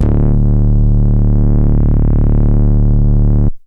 VEC1 Bass Long 26 D.wav